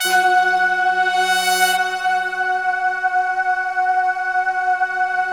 Index of /90_sSampleCDs/Optical Media International - Sonic Images Library/SI1_BrassChoir/SI1_SlowChoir
SI1 BRASS0FR.wav